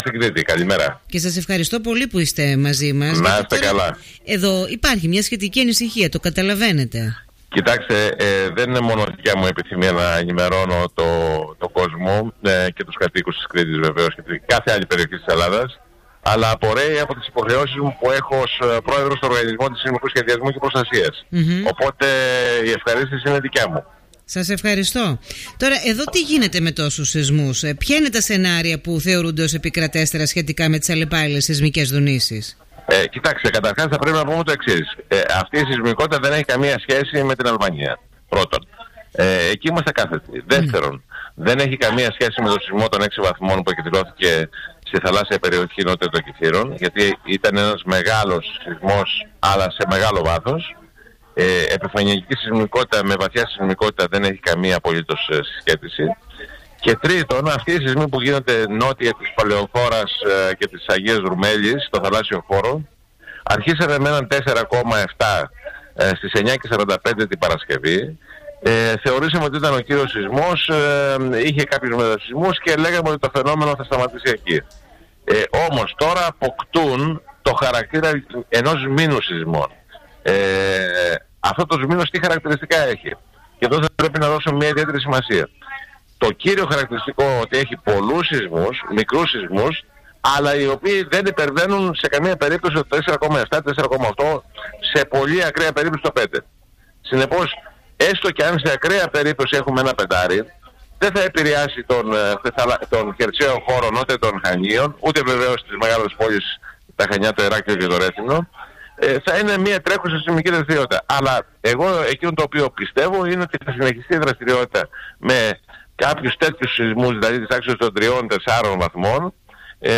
Ακούστε εδώ ολόκληρη την συνέντευξη του Πρόεδρου του τμήματος Γεωλογίας και Γεωπεριβάλλοντος, Ευθύμη Λέκκα στον Politica 89.8: